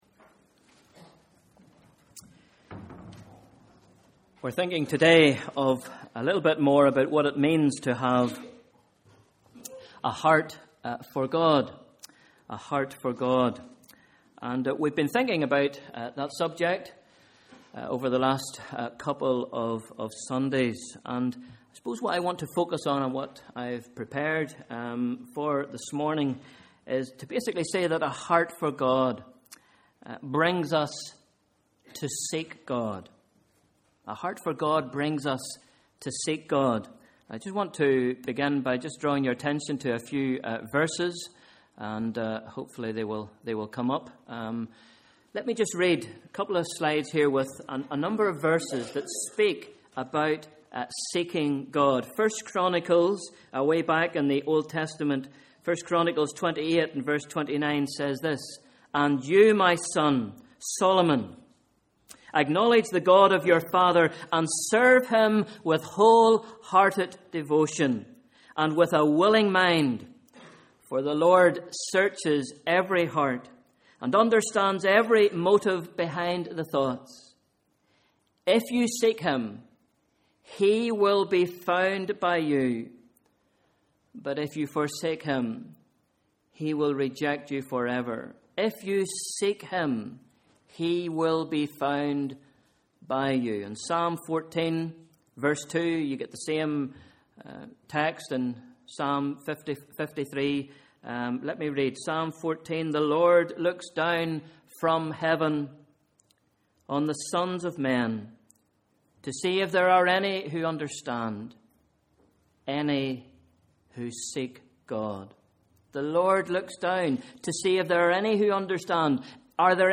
Sunday 3rd March 2013: Morning Service